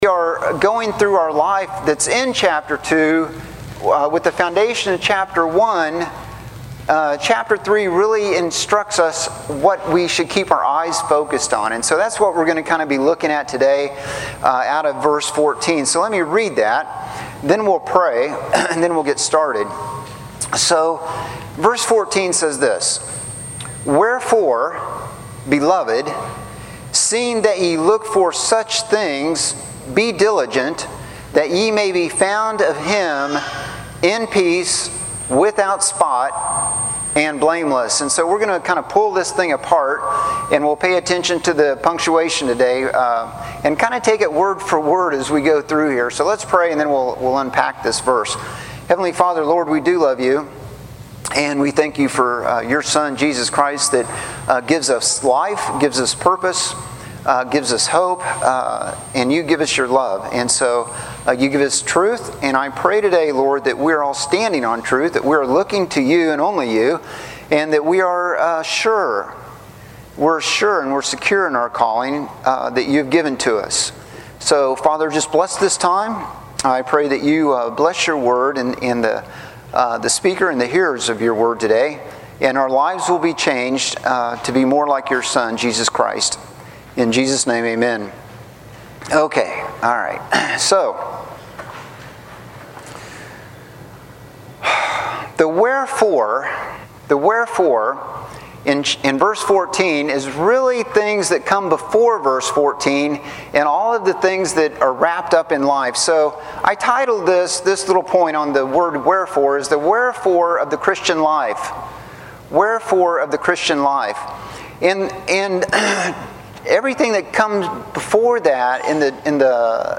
Special Message ~ Guest Preacher